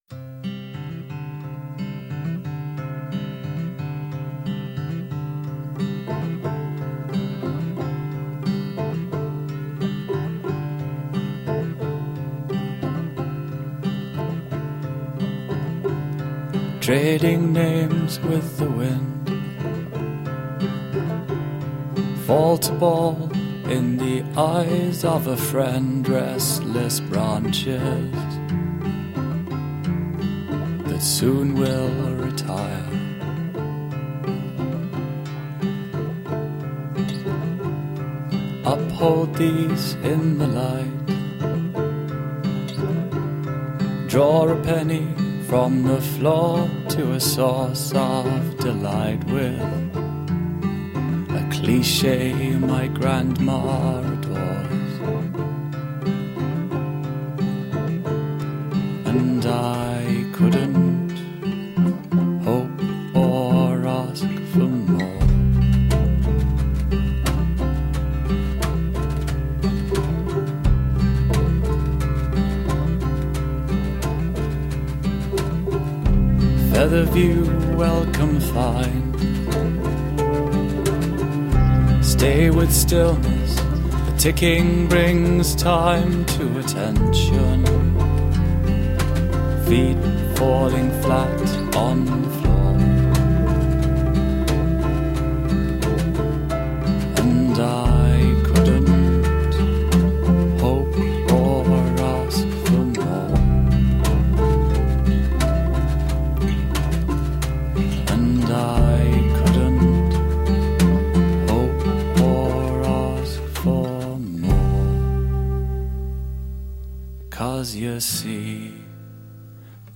Gently experimental nu-folk.
lush instrumentation
Tagged as: Alt Rock, Folk-Rock, Folk